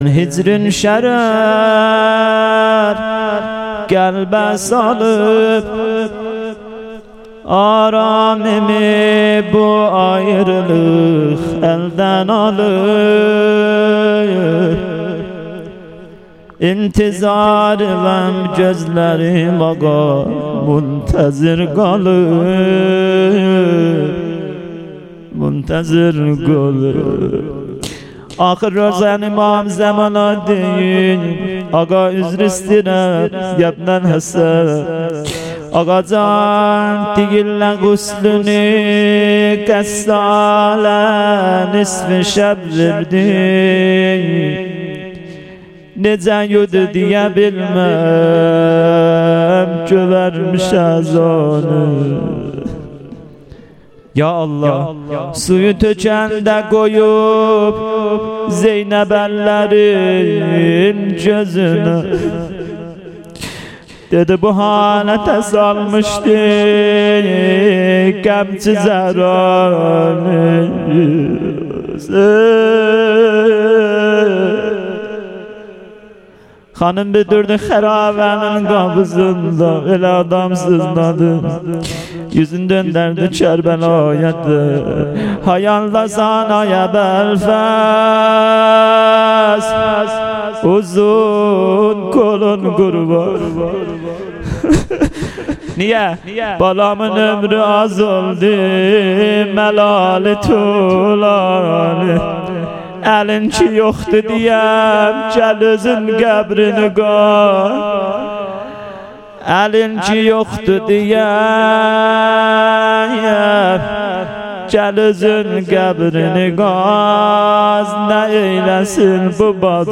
شب سوم محرم 98 - روضه پایانی